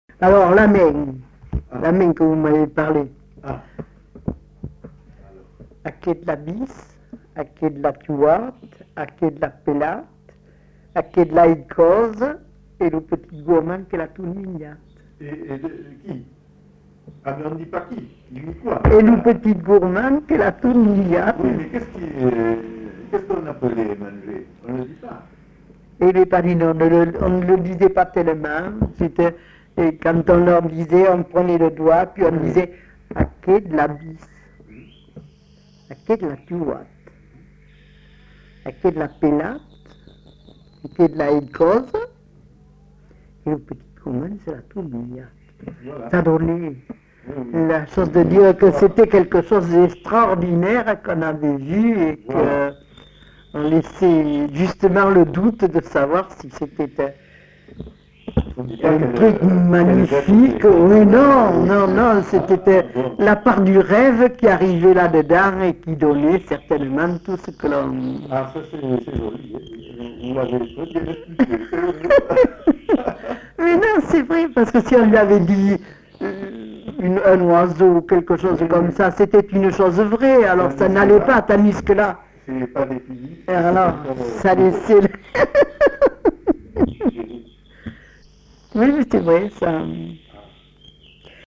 Lieu : Grignols
Genre : forme brève
Effectif : 1
Type de voix : voix de femme
Production du son : lu
Classification : formulette enfantine